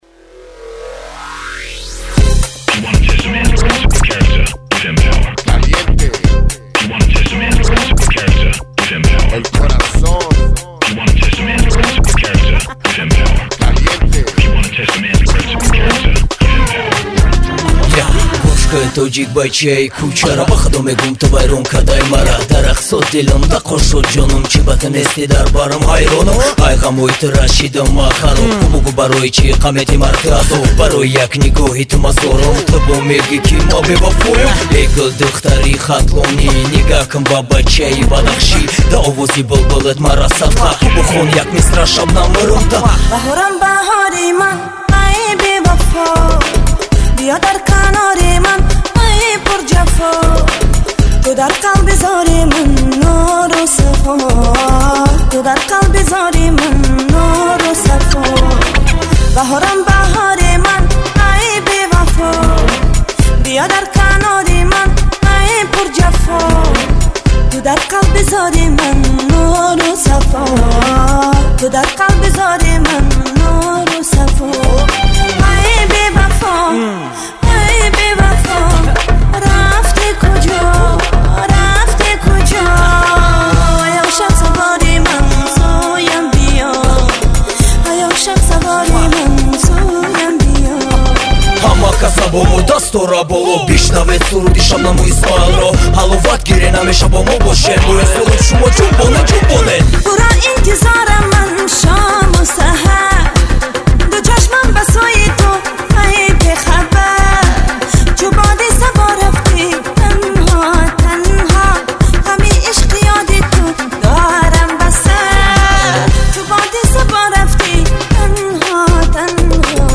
Главная » Файлы » Каталог Таджикских МР3 » Эстрада